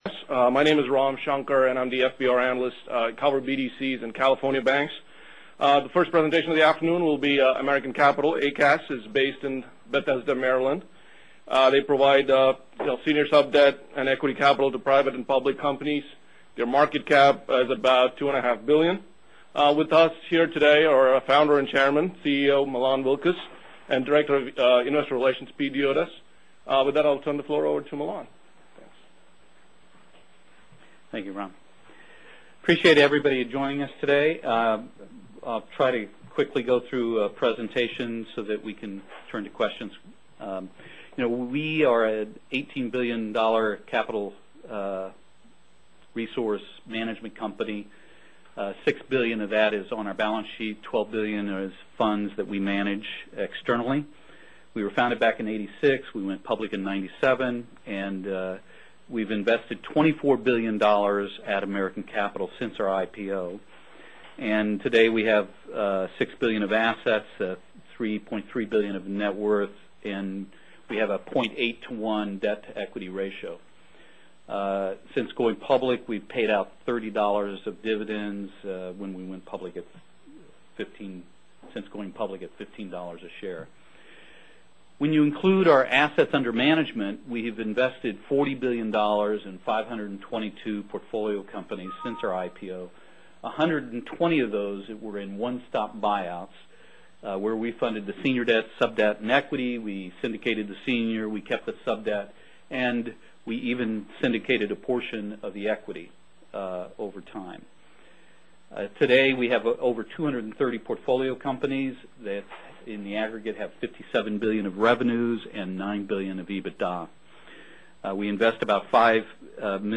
$$ ACAS Presentation and Q&A
Listen to the following call and the Q&A at the end. Pay attention to the de-leveraging that has taken place, the current marks on ECAS vs true value (slide 36), and the marks they have on sold investments (2.9% difference, meaning BV is rock solid).